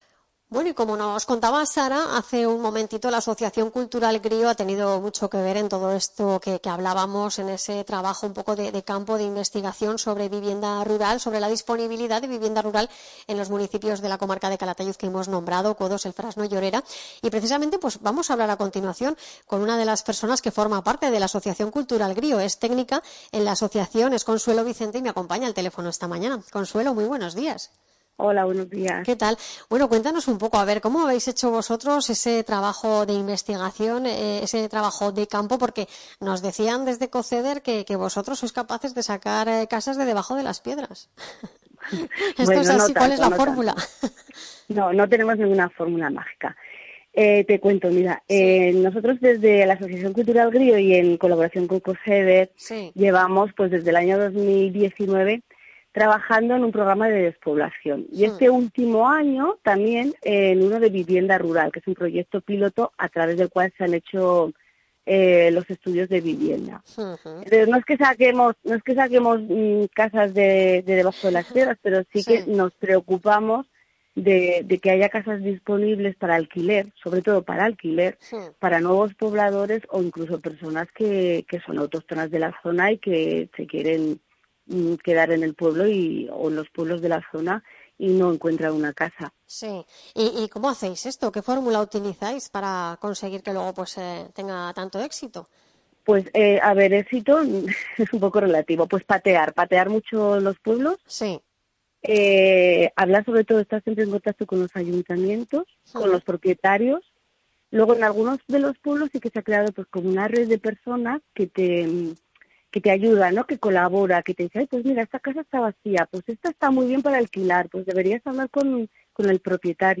Entrevista Grío